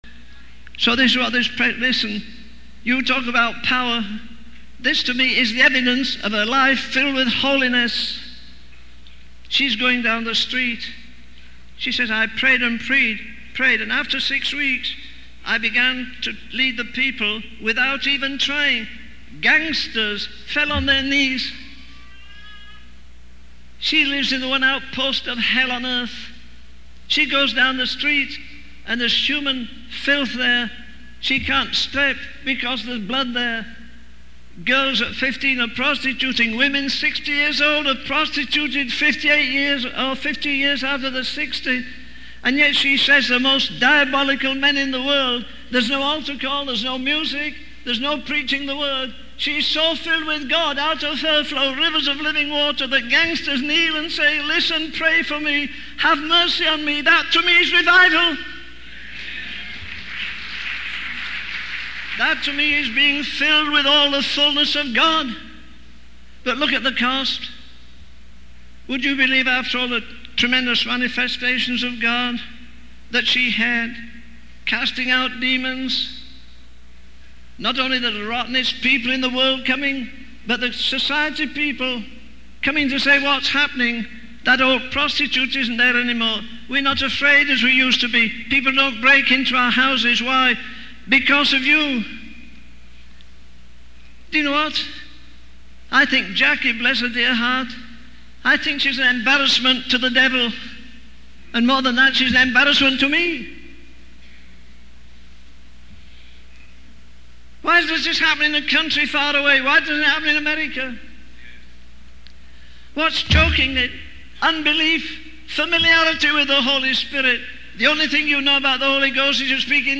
In this sermon, the speaker emphasizes the importance of having a vision and being filled with compassion. He refers to the story of Isaiah, who had a vision of God and recognized his own depravity and duty to the world.